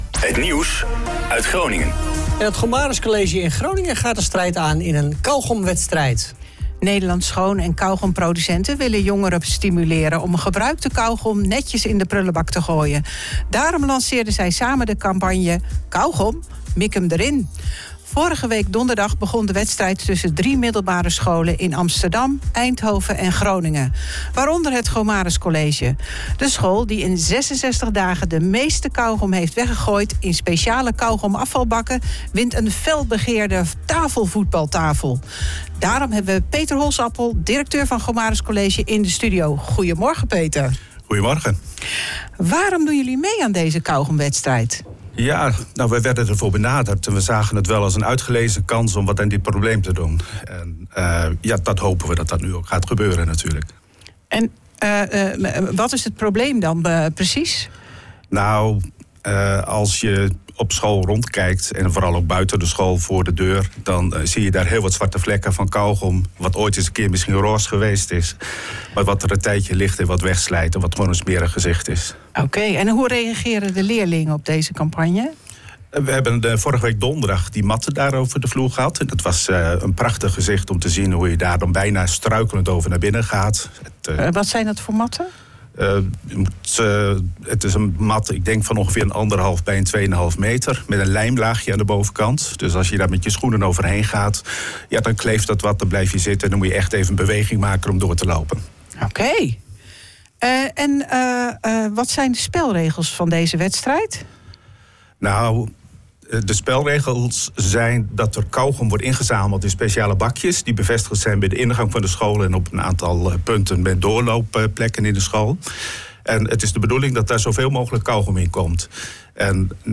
Interview-Gomarus-College-doet-mee-aan-kauwgomwedstrijd.mp3